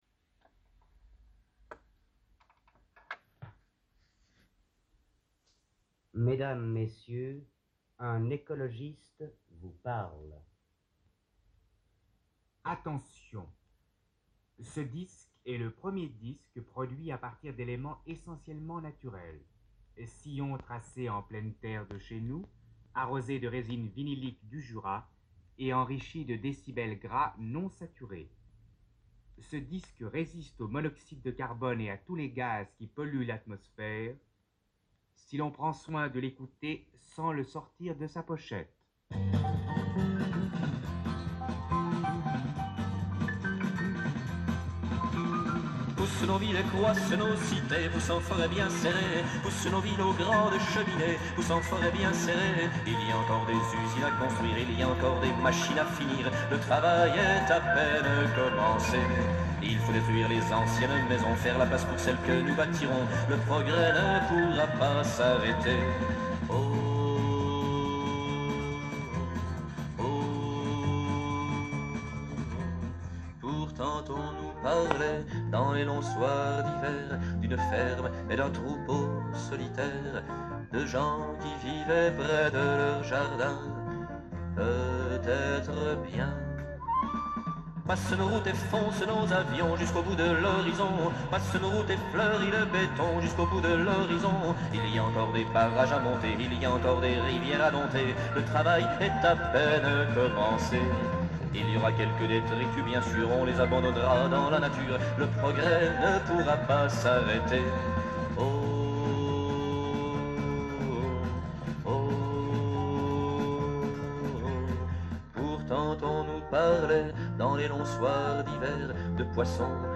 Enregistrement Sound Studio Souncraft Bienne
et Radio Suisse romande, Studio de Lausanne.